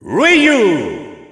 Announcer pronouncing Ryu's name in French.
Ryu_French_Announcer_SSBU.wav